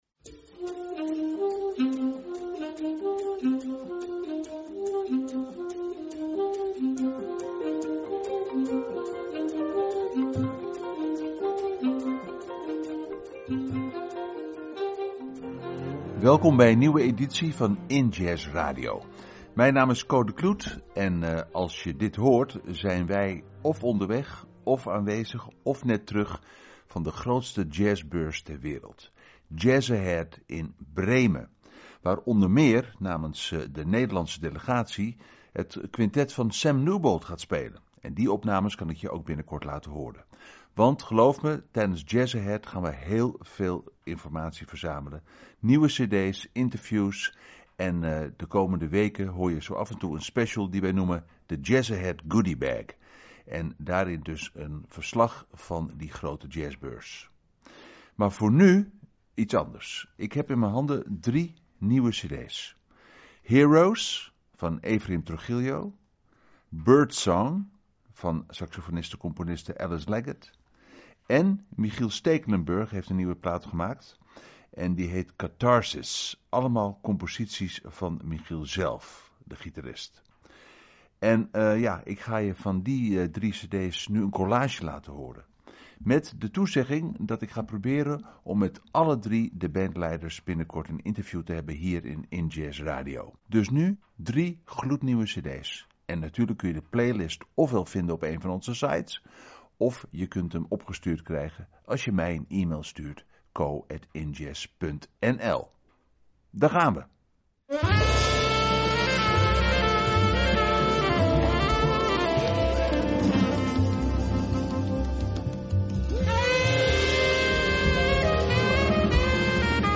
Centraal staat het promoten van Nederlandse muziek rondom jazz, global en meer. Met deze keer aandacht voor nieuwe releases.